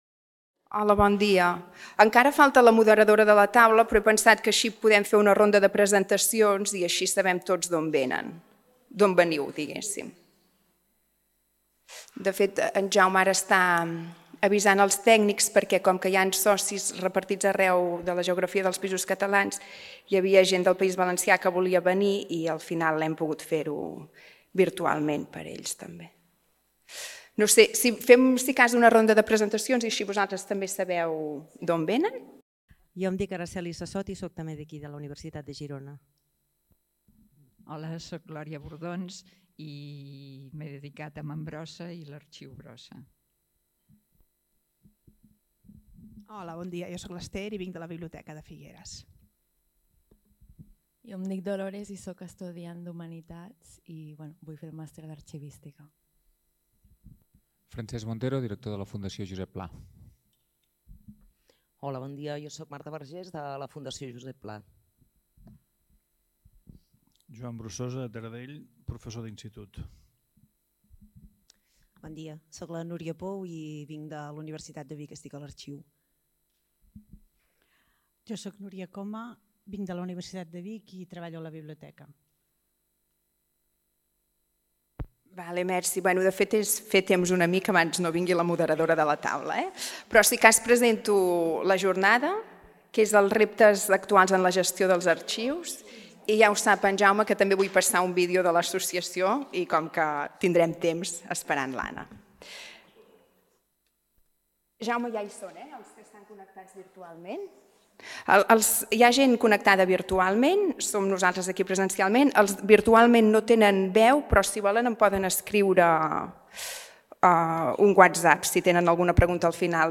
Taula rodona realtizada dins de la Jornada de l'associació Espais Escrits dedicada als reptes actuals en la gestió dels arxius.
Enguany, espais escrits celebra els 20 anys i han fet un vídeo commemoratiu que es visualitza abans de començar la taula rodona.
Repositori i preservació digital Primer cada convidat tracta els diversos punts i al final hi ha un torn de preguntes o de reflexions per part del públic assitent  Aquest document està subjecte a una llicència Creative Commons: Reconeixement – No comercial – Compartir igual (by-nc-sa) Mostra el registre complet de l'element